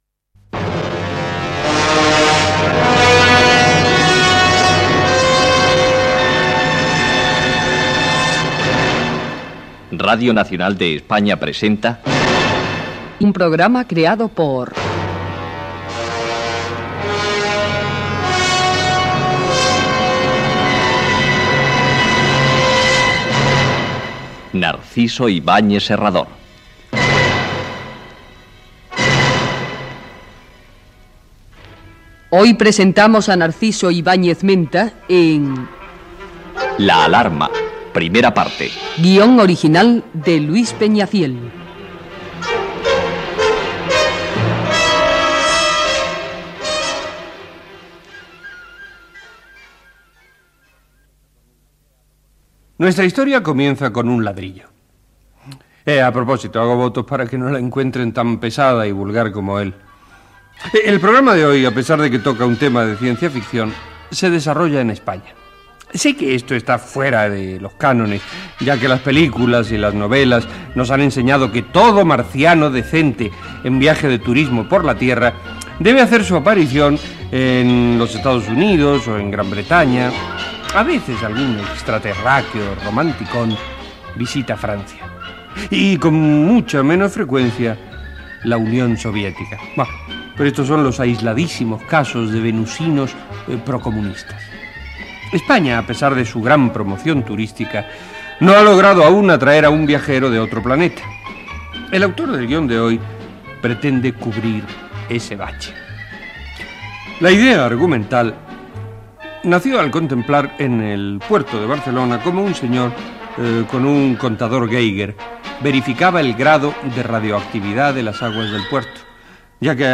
70d8f6986f36373acbe7c805fcc0e5b9b8cf11a7.mp3 Títol Radio Nacional de España Emissora Radio Nacional de España Barcelona Cadena RNE Titularitat Pública estatal Nom programa Historias para imaginar Descripció "La alarma". Careta, introducció de Chicho Ibáñez Serrador. Obra i crèdits finals Gènere radiofònic Ficció